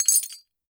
GLASS_Fragment_02_mono.wav